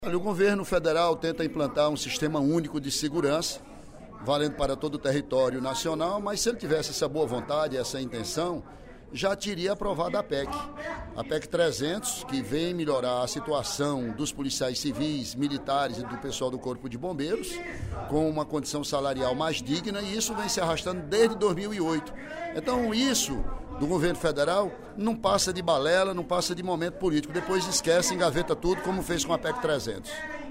O deputado Ely Aguiar (PSDC) questionou, durante o primeiro expediente da sessão plenária desta quarta-feira (07/05), o projeto de lei nº 3734/12, do Poder Executivo, que tramita na Câmara dos Deputados, e cria o Sistema Único de Segurança Pública (Susp), disciplinando a organização e o funcionamento dos órgãos responsáveis pela segurança pública.